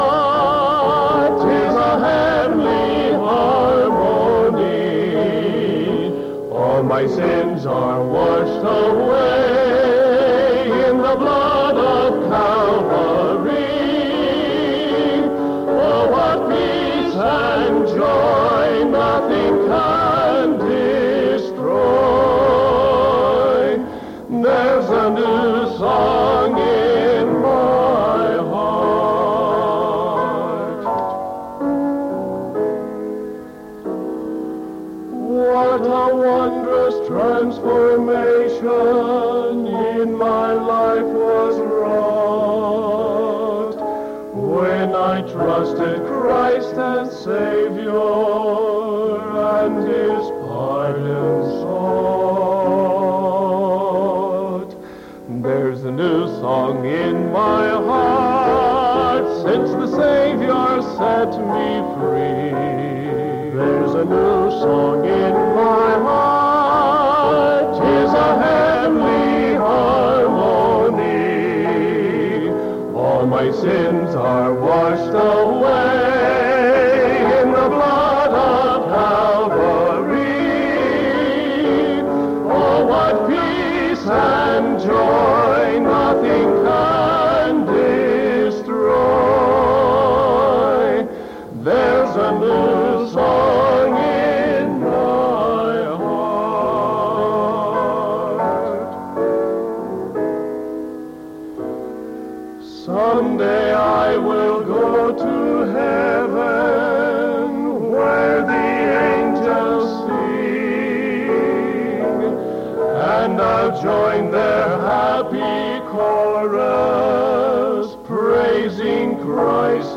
Sermon June 29th 1975 PM